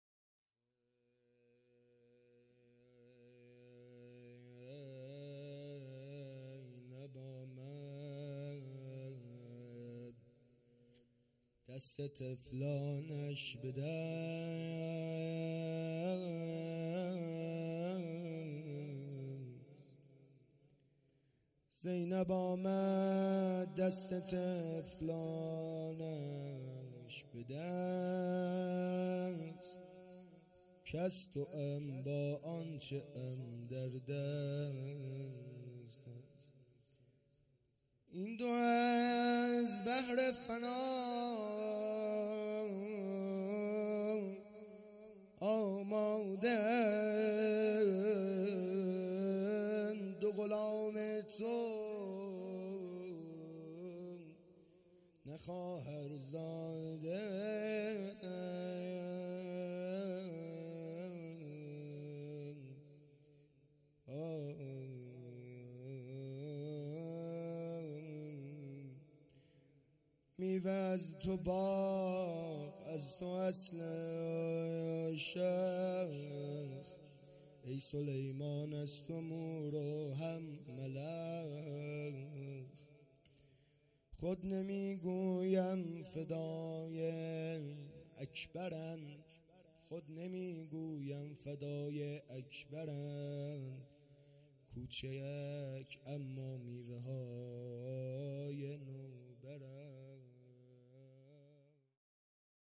روضه پایانی
محرم الحرام ۱۴۴۳